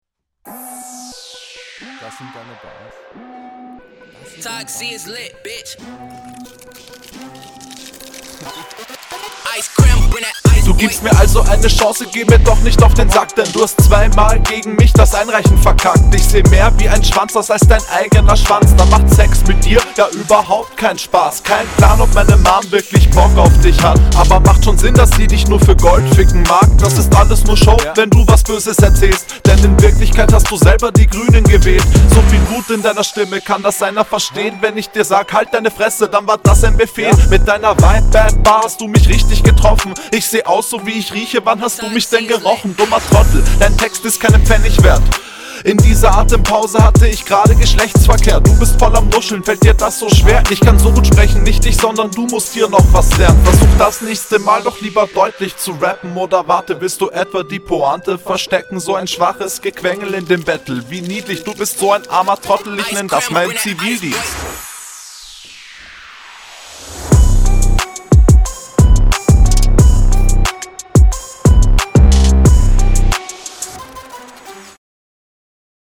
Sehr witzige Konter dabei, die Atempause eeeetwas selfdissig, aber trotzdem funny funny.